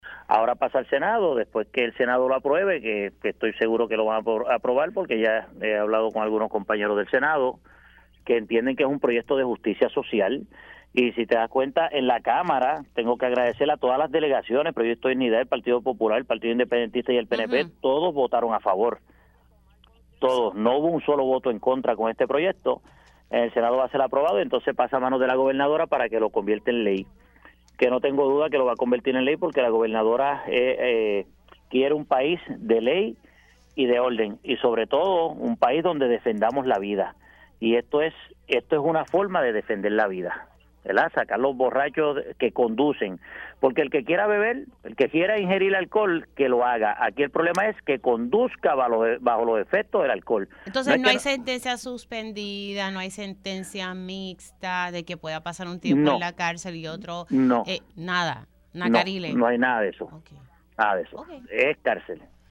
506-FERNANDO-SANABRIA-REP-PNP-MEDIDA-AHORA-PASA-AL-SENADO-NO-HABRIA-DERECHO-A-SENTENCIA-SUSPENDIDA.mp3